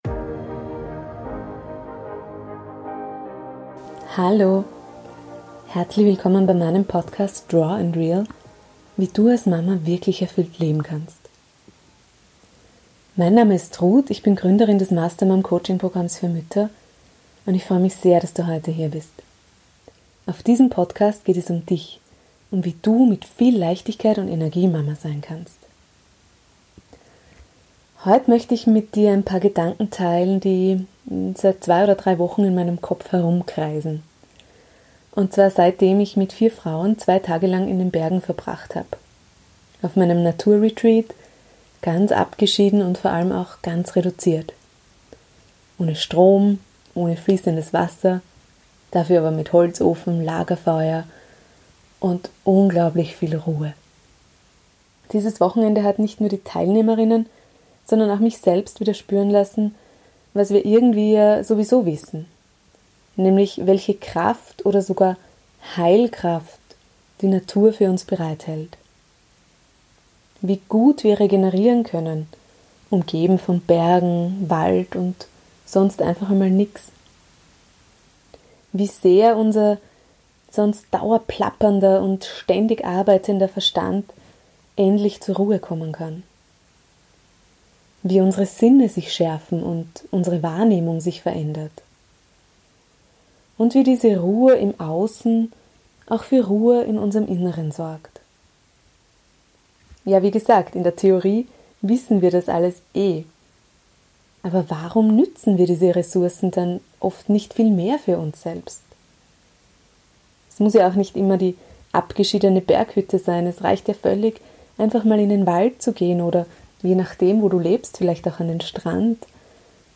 #64 Meditation für dein Grounding ~ raw and real Podcast